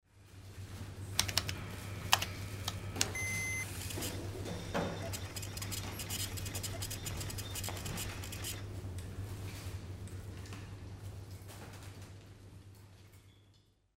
Marcar y sacar el ticket de una caja registradora de un supermercado
caja registradora
Sonidos: Industria